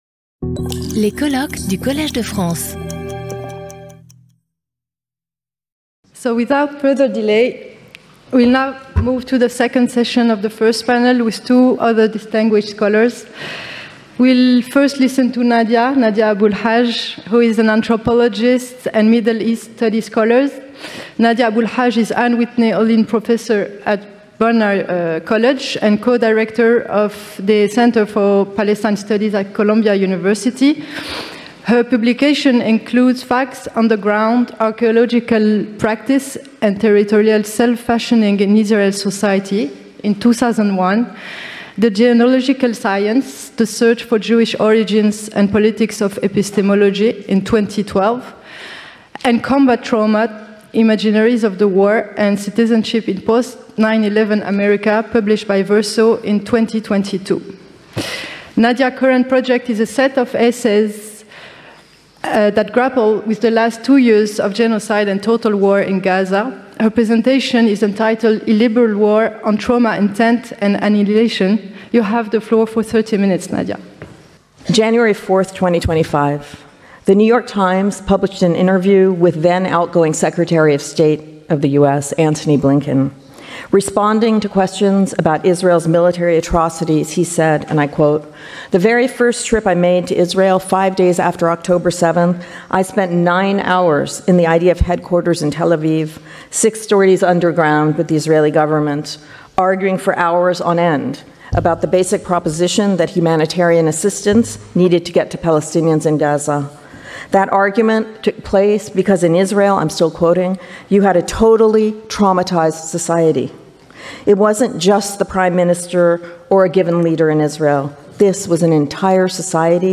Lecture audio
Sauter le player vidéo Youtube Écouter l'audio Télécharger l'audio Lecture audio Cette vidéo est proposée dans une version doublée en français.